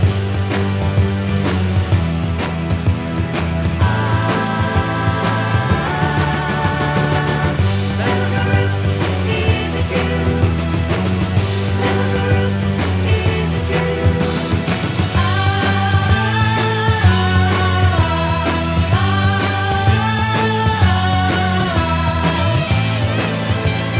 Glam Rock